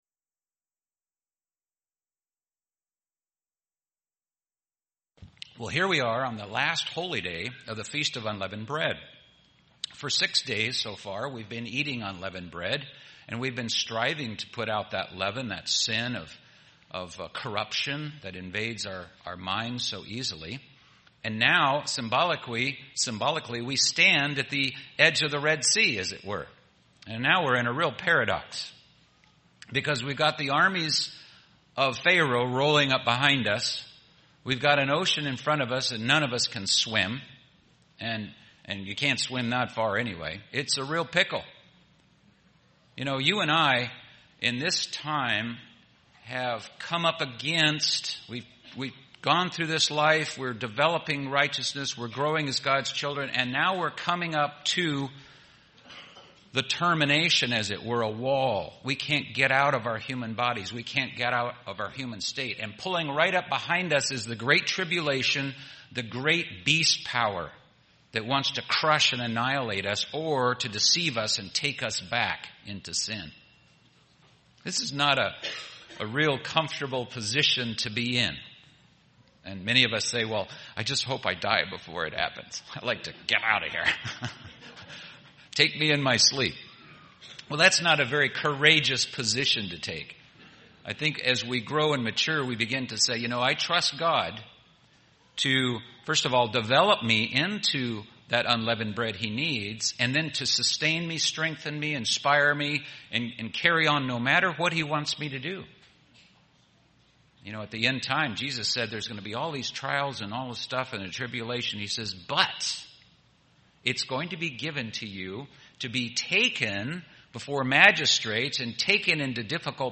We are to be still and know that He is God. This message was given on the Last Day of Unleavened Bread.